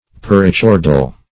Search Result for " perichordal" : The Collaborative International Dictionary of English v.0.48: Perichordal \Per`i*chor"dal\, a. Around the notochord; as, a perichordal column.
perichordal.mp3